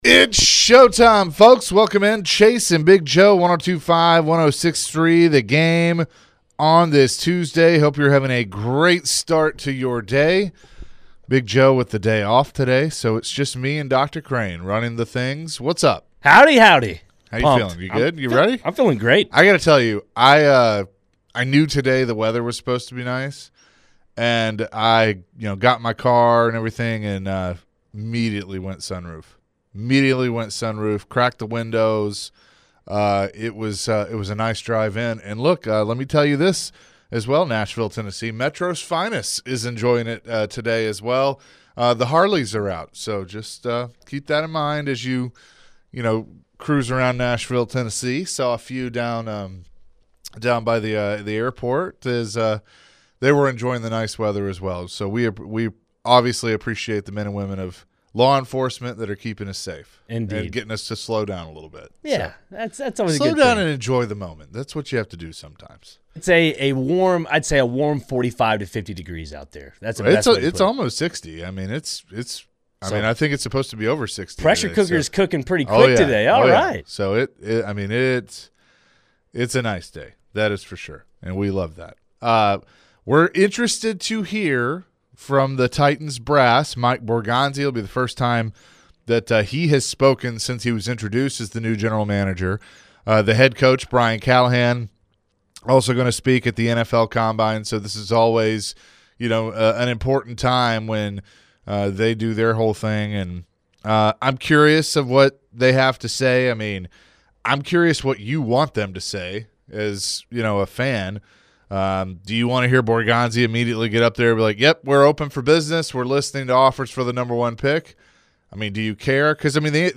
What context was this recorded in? Later in the hour, the Tennessee Titans held a press conference live from the NFL Combine.